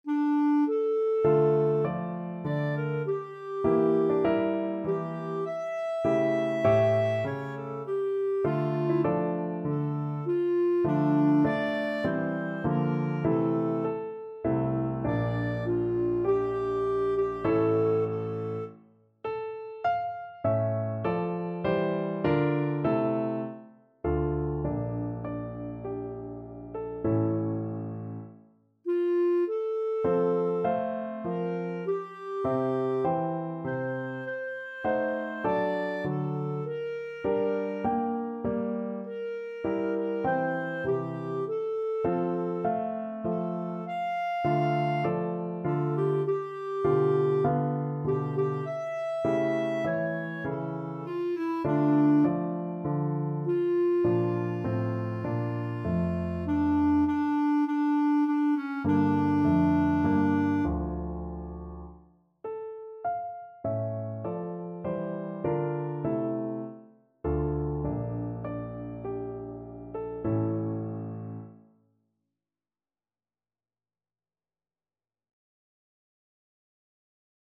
Clarinet
D minor (Sounding Pitch) E minor (Clarinet in Bb) (View more D minor Music for Clarinet )
Largo =c.100
4/4 (View more 4/4 Music)
Classical (View more Classical Clarinet Music)